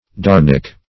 darnic - definition of darnic - synonyms, pronunciation, spelling from Free Dictionary
Search Result for " darnic" : The Collaborative International Dictionary of English v.0.48: Darnex \Dar"nex\ (d[a^]r"n[e^]ks), Darnic \Dar"nic\ (d[a^]r"n[i^]k), n. Same as Dornick .
darnic.mp3